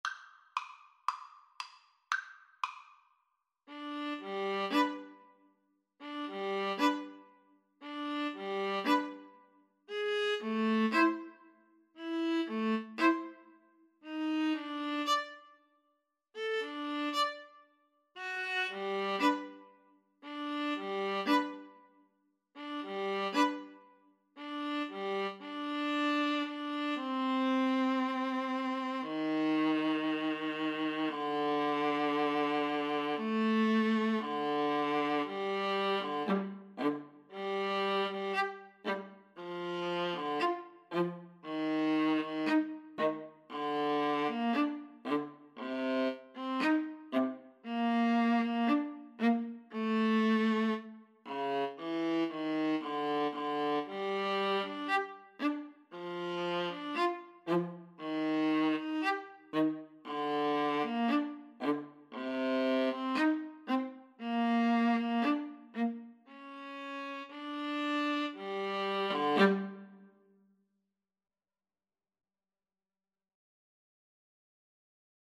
tango song
2/4 (View more 2/4 Music)